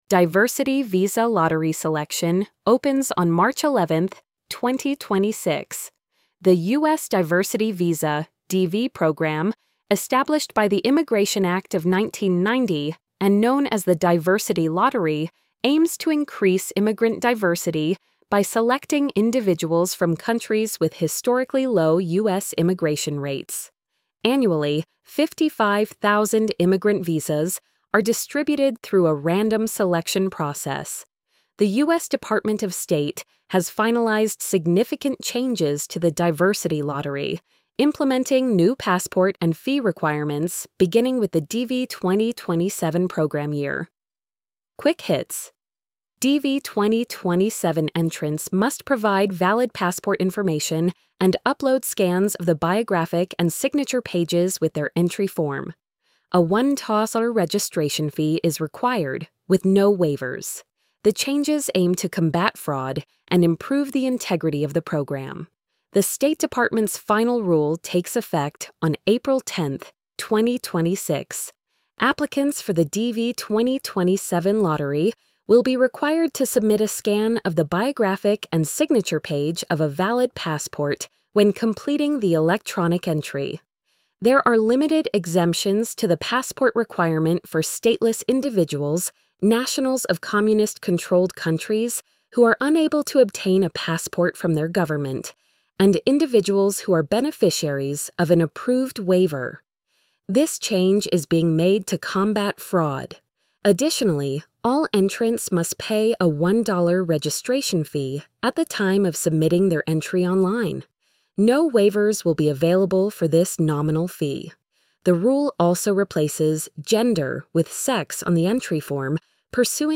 post-86311-tts.mp3